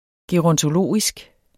Udtale [ geʁʌntoˈloˀisg ]